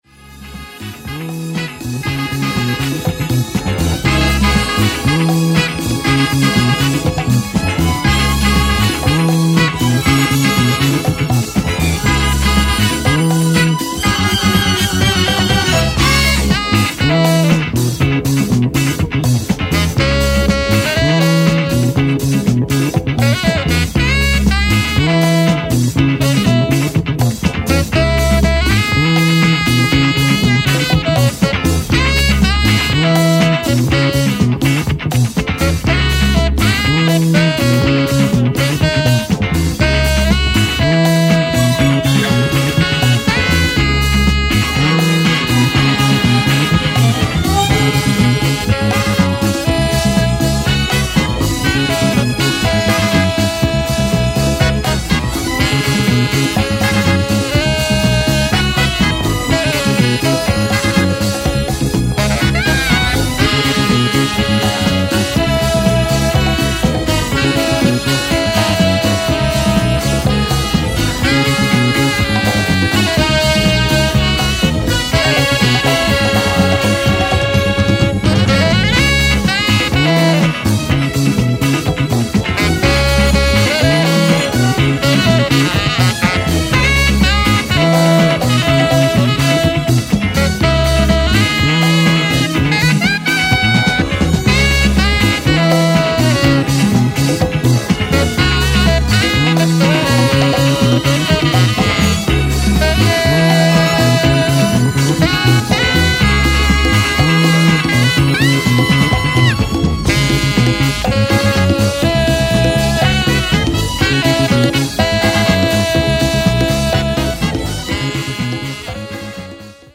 A wild mix of disco, jazz, funk, gospel and fusion edits
Disco House Soul Funk